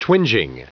Prononciation du mot twinging en anglais (fichier audio)
Prononciation du mot : twinging